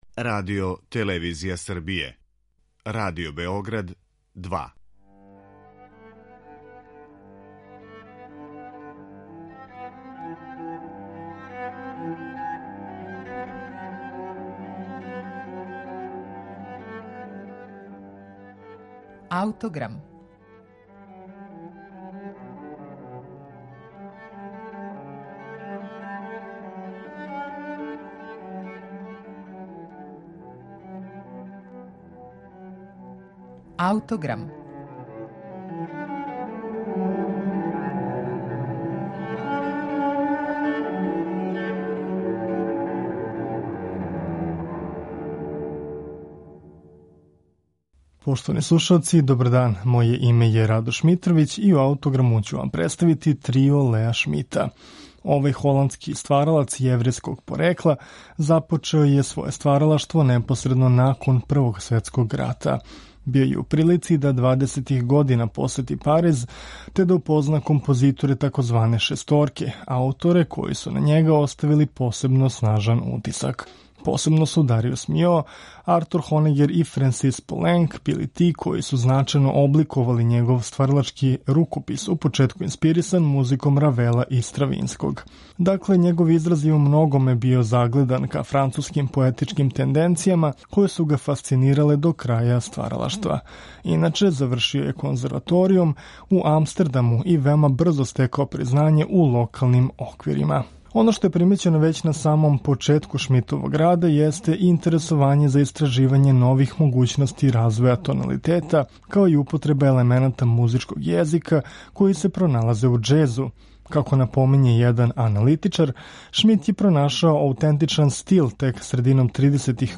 Ми ћемо слушати његов Трио, писан за флауту, виолину и харфу, посвећен харфисткињи Рози Шпир.